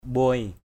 /ɓʊaɪ/ mbuai =O& [Cam M] (đg.) đỡ đẻ = aider à l’accouchement. assist in the childbirth. muk mbuai m~K =O& bà mụ (bà đỡ đẻ) = sage-femme. midwife.